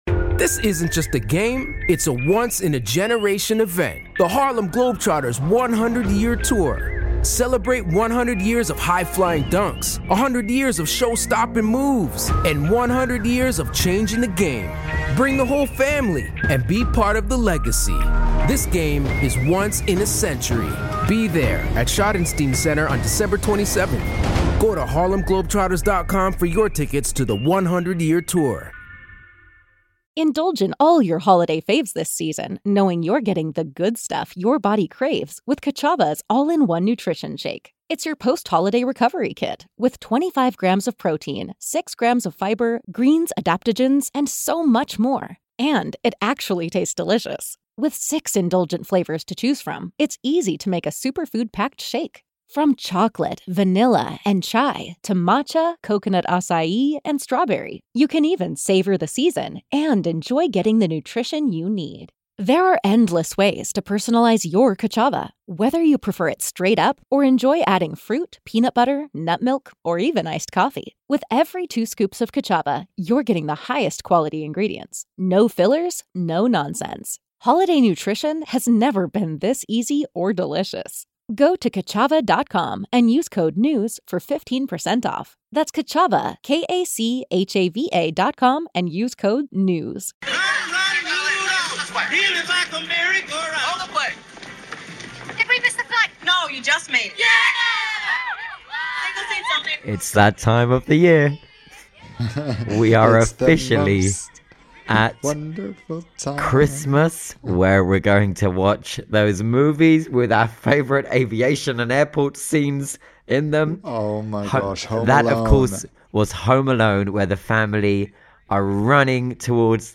a fresh take on aviation & travel's hot topics and beyond, complete with lively discussion, listener Q&As, and a whole lot of fun along the way.